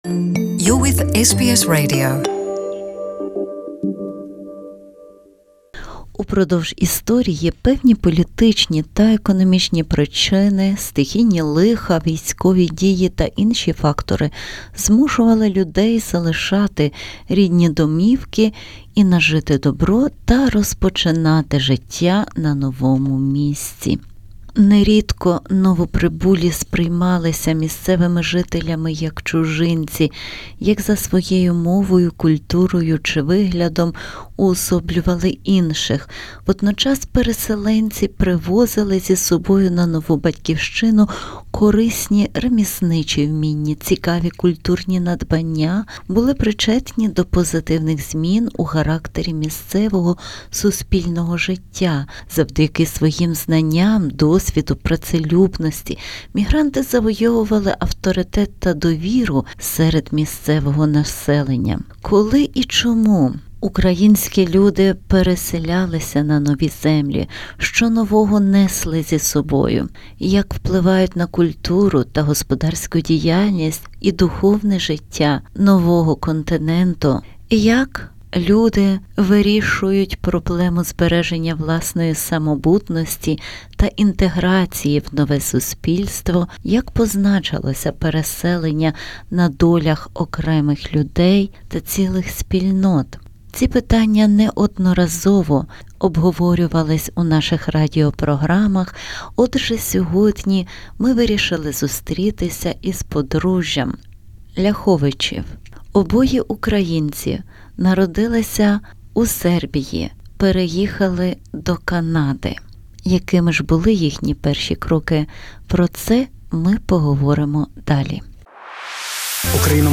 Інтерв'ю